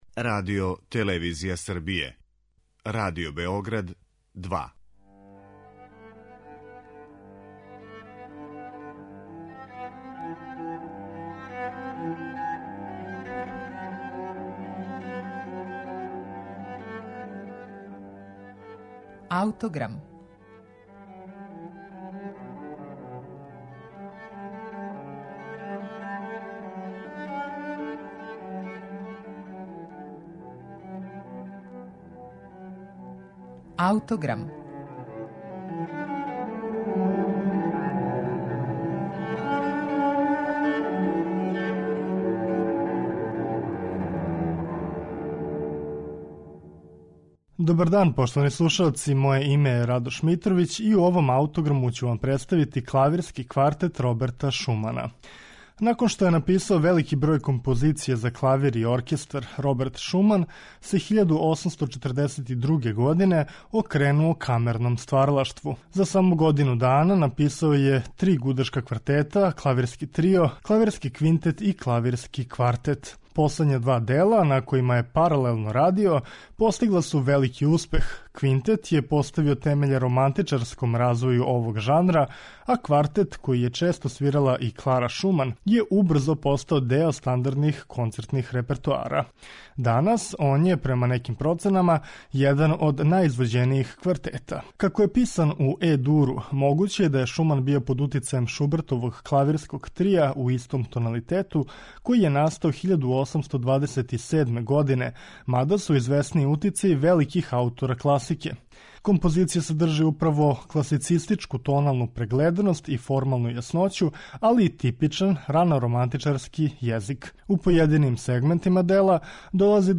Клавирски квартет Роберта Шумана слушаћете у извођењу Менахема Преслера и гудачког квартета Емерсон.